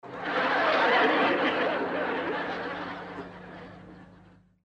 PLAY RISAS CHAVO
risas-chavo.mp3